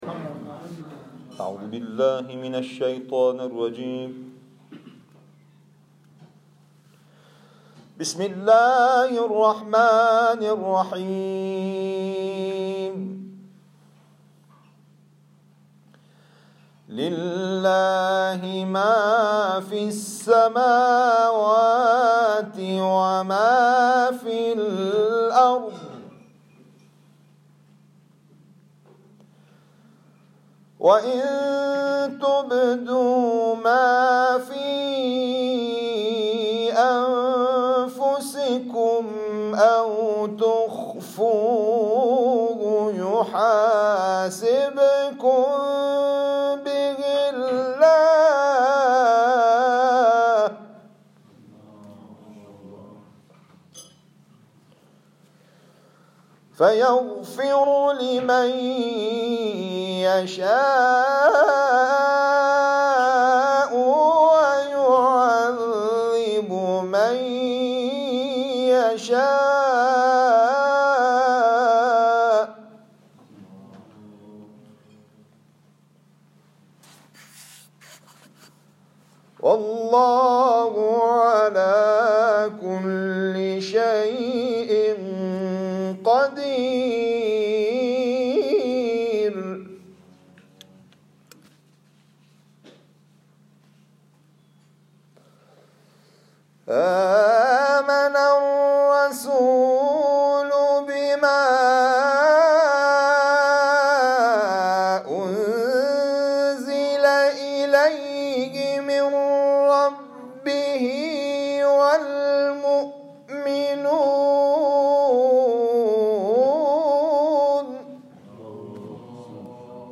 این تلاوت کوتاه هشت دقیقه‌ای، روز 6 اردیبهشت ماه اجرا شده است.